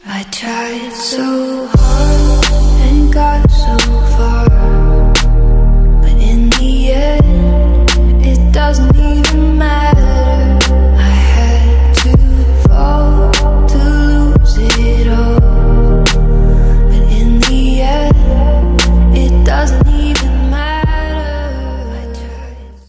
Thể loại nhạc chuông: Nhạc âu mỹ